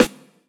ETWY_SNR.wav